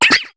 Cri de Sepiatop dans Pokémon HOME.